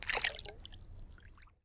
water-splash-06
Category 🌿 Nature
bath bathroom bubble burp click drain dribble dripping sound effect free sound royalty free Nature